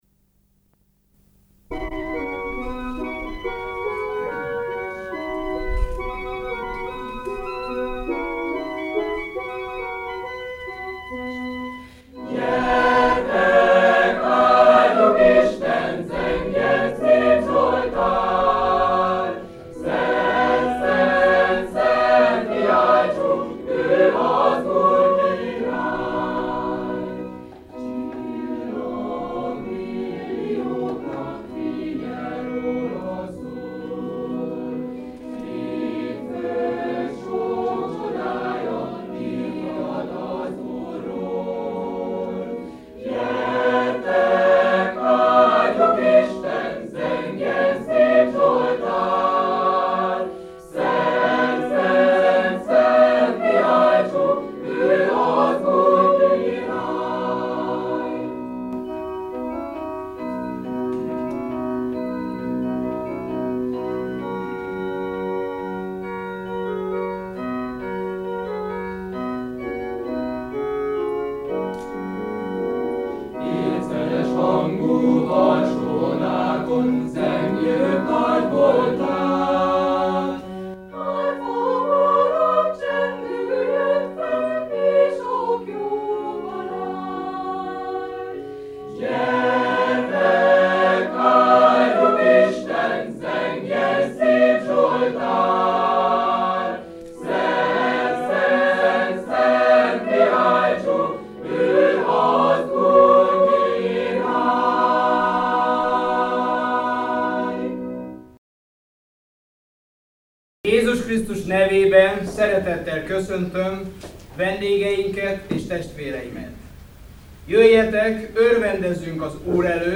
Média Videók és hanganyagok Minden kórus fellépéséről, koncertjéről készülnek felvételek, melyek őrzik az emlékeket.
1989 - Balatonlelle - Zenei áhitat
White_korus_1989_balatonlelle.mp3